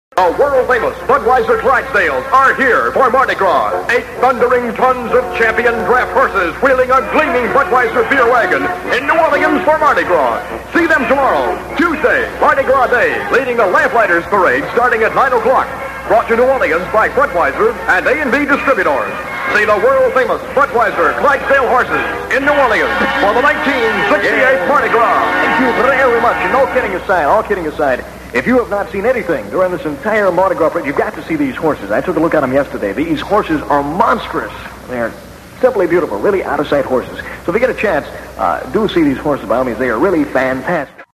Mid 60's Radio Commercials heard on WTIX